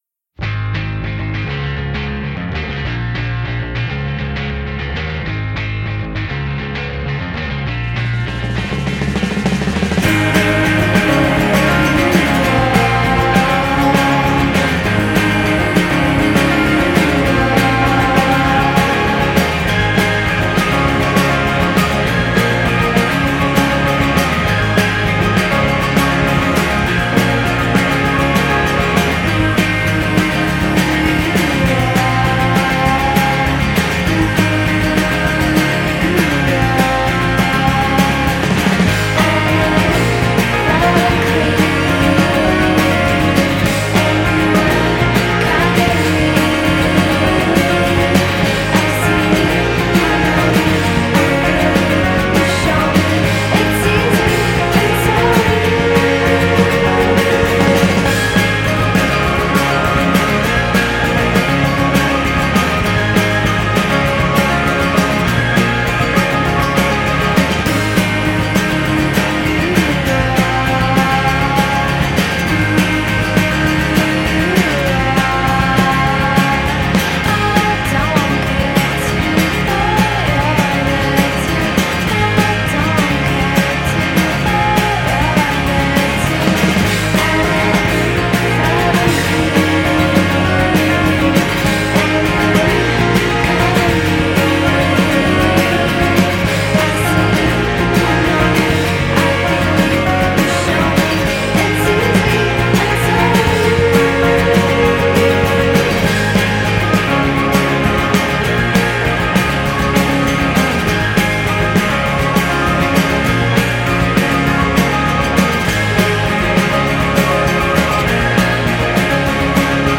L'indiepop delle chitarre ostinate a pugni chiusi